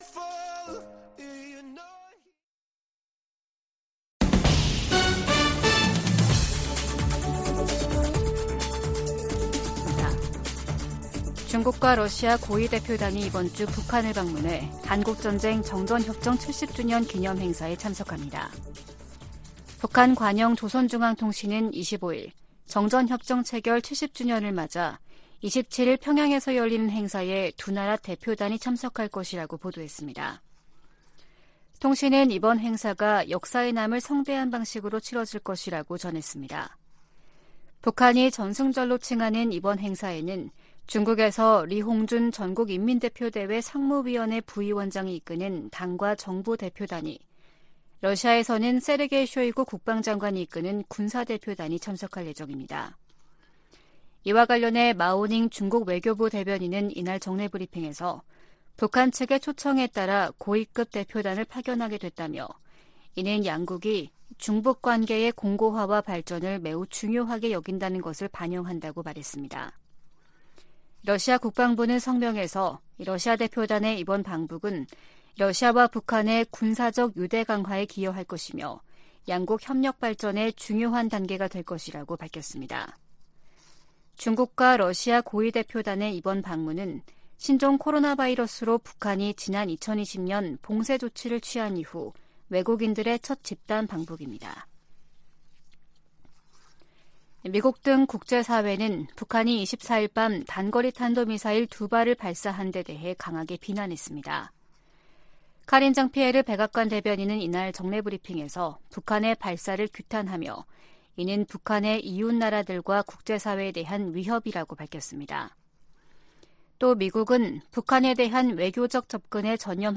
VOA 한국어 아침 뉴스 프로그램 '워싱턴 뉴스 광장' 2023년 7월 26일 방송입니다. 백악관과 미 국무부는 월북 미군과 관련해 여전히 북한의 응답을 기다리는 중이며, 병사의 안위와 월북 동기 등을 계속 조사하고 있다고 밝혔습니다. 북한이 24일 탄도미사일 2발을 동해상으로 발사했습니다.